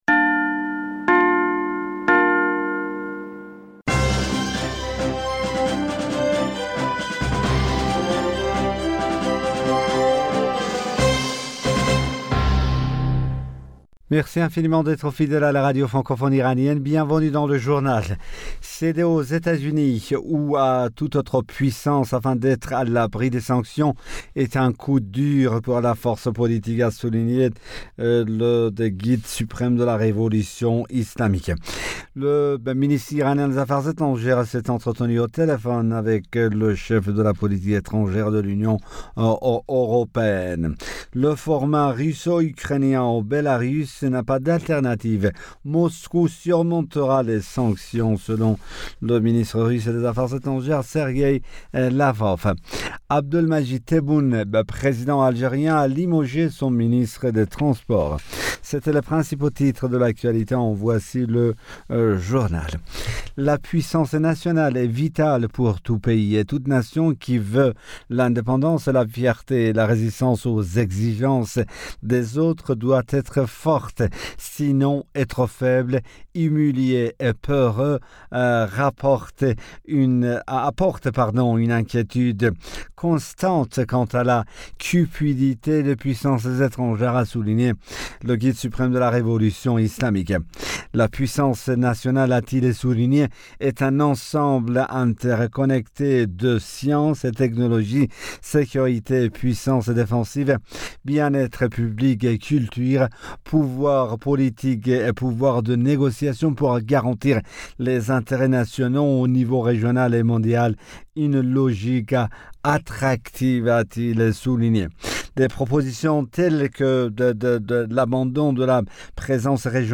Bulletin d'information Du 11 Mars 2022